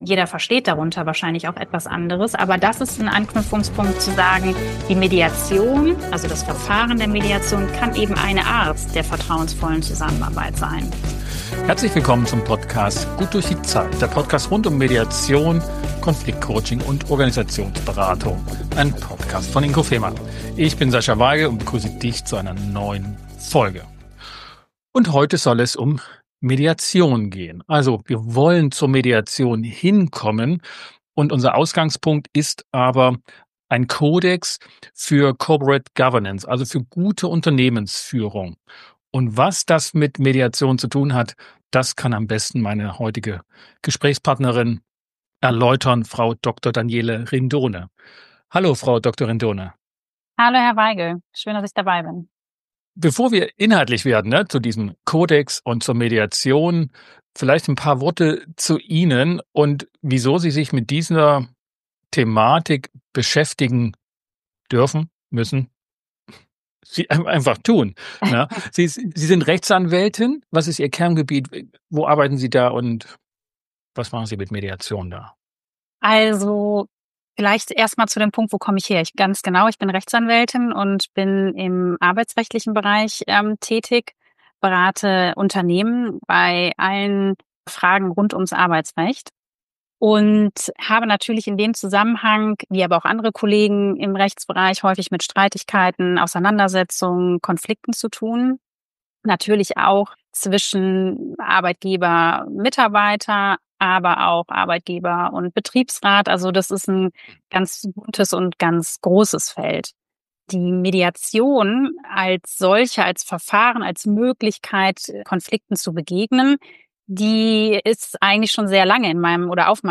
#165 - Praxis von Konfliktmanagementsystemen. Im Gespräch